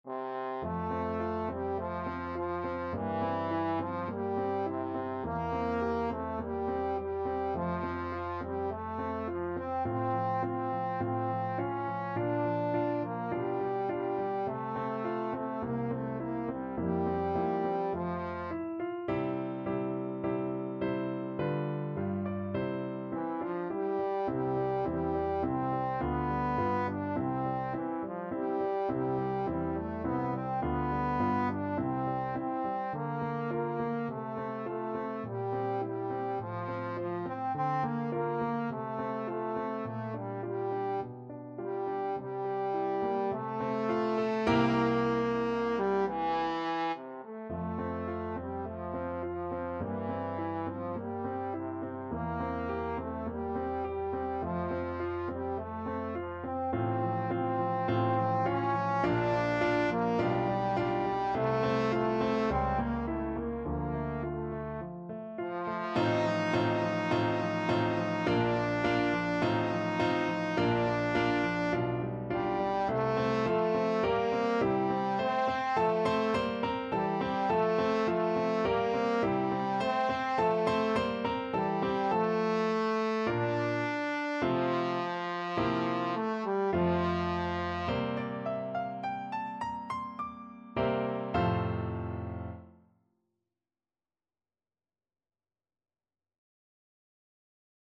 Trombone
F major (Sounding Pitch) (View more F major Music for Trombone )
2/4 (View more 2/4 Music)
= 52 Andante
Classical (View more Classical Trombone Music)